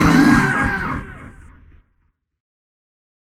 PixelPerfectionCE/assets/minecraft/sounds/mob/horse/zombie/hit2.ogg at c56acfee49e7e1bcd779741dcd49ed8fe864c119